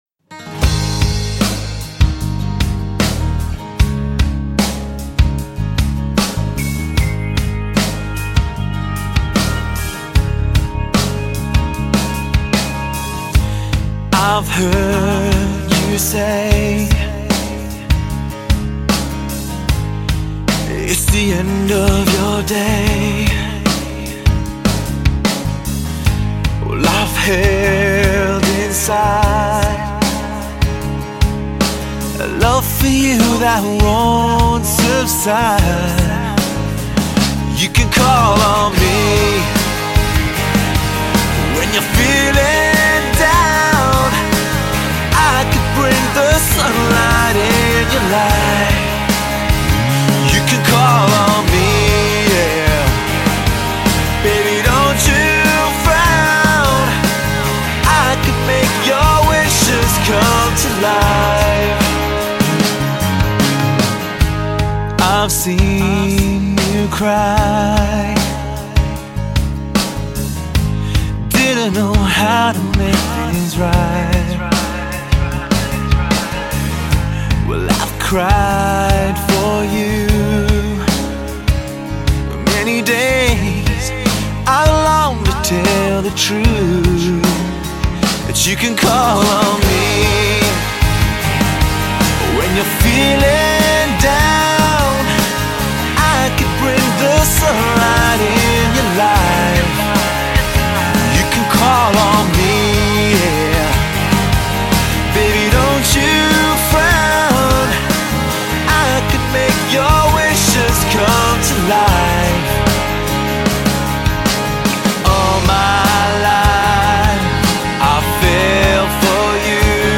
میکس و مسترینگ - Mixing & Mastering
mrhlh_y_myks_w_mstryng_fc72e23cc1.mp3